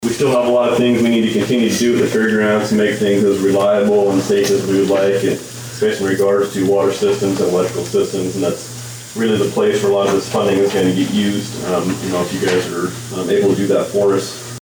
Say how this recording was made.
Humboldt, IA – The Humboldt County Memorial Society requested funding for the Humboldt County Fair in the amount of $25,000 at this week’s Humboldt County Board of Supervisor’s meeting.